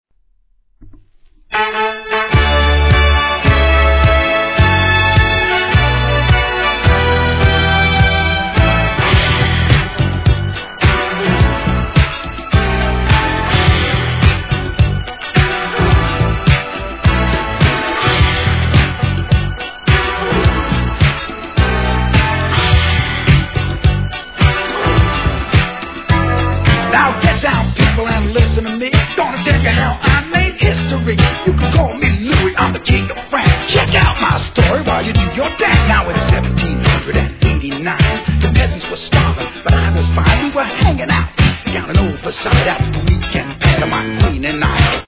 PARTYにもってこいの大ヒットDISCOナンバー！！！